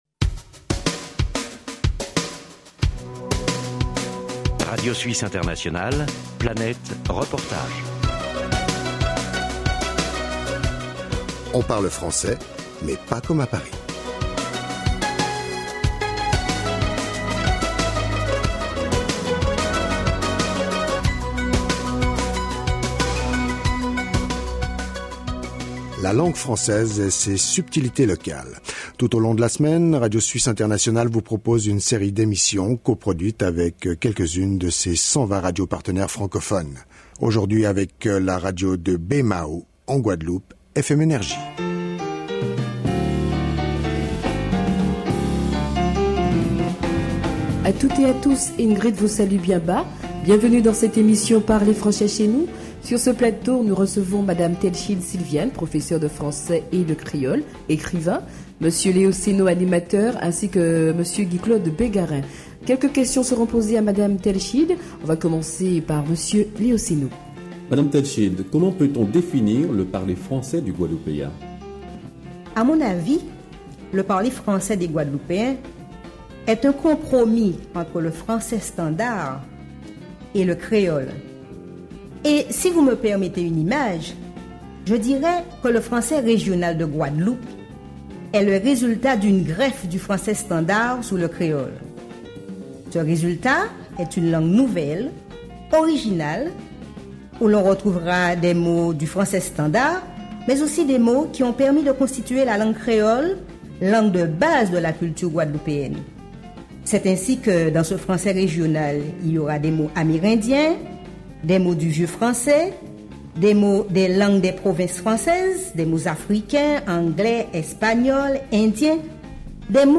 (Archives Radio Suisse Internationale, 1997, série «Le français parlé»).